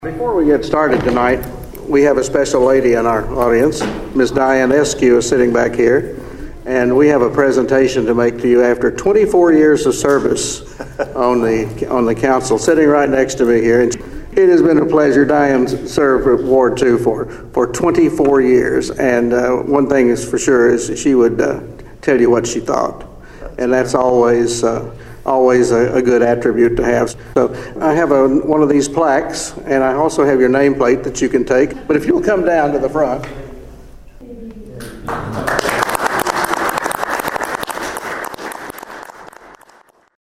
During Tuesday’s monthly Council meeting at the Municipal Building, Mayor Terry Hailey made the presentation.(AUDIO)